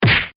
Punch Effect